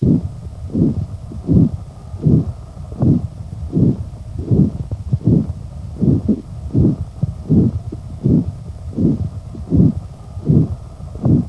Doppler
De slagaders van de benen kunnen met een Continues Wave (CW) doppler-apparaat worden onderzocht.
signaal kunnen bij gezonde personen twee of drie fasen gezien worden. Met toenemende vernauwing zullen er distaal van deze vernauwing monofasiche signalen gezien worden.
ArtSouffle.wav